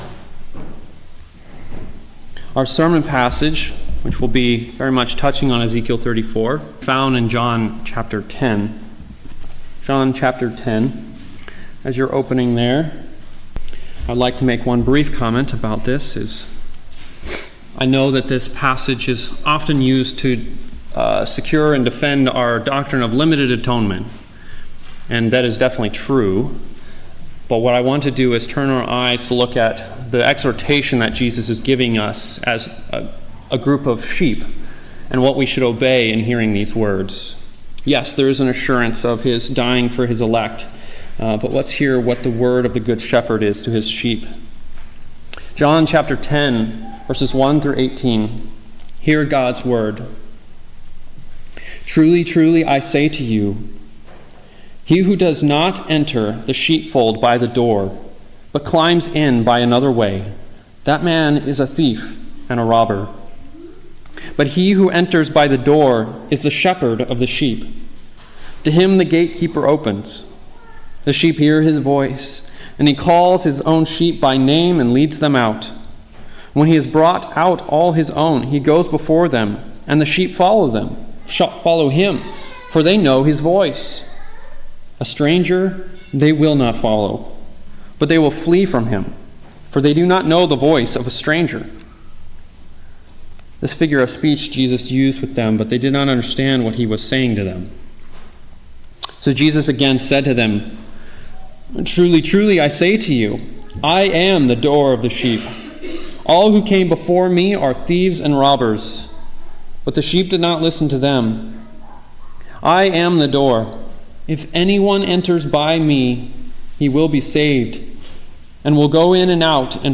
Morning Sermon